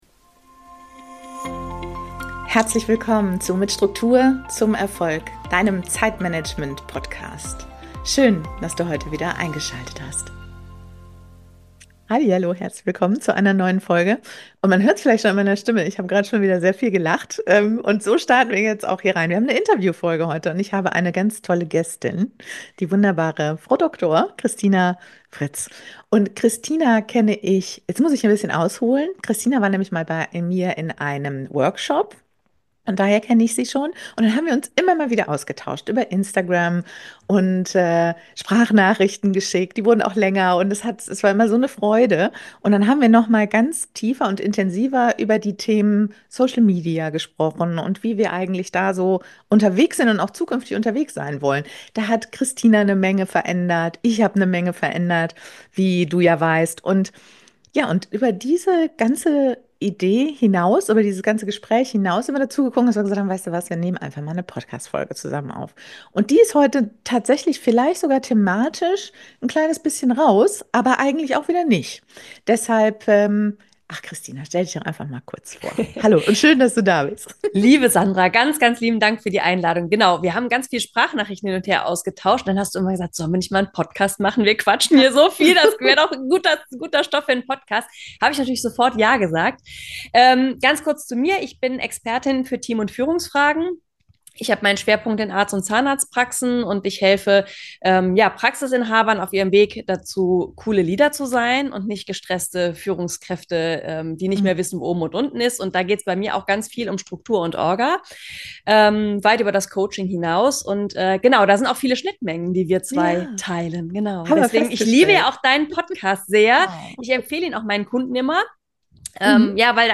Wir sprechen über die Frage: Wie spitz darf eine Positionierung sein, ohne dass dabei die eigene Vielfalt verloren geht? Wir teilen unsere Gedanken zu Social Media, über Druck im Marketing, was uns wirklich ins Tun gebracht hat – und warum Struktur nichts mit Kontrolle, sondern alles mit Freiheit zu tun hat. Eine Folge voller ehrlicher Einblicke, kluger Gedanken und praktischer Erfahrungen zweier Frauen, die gelernt haben, ihren eigenen Weg zu gehen – in der Arbeit und im Leben.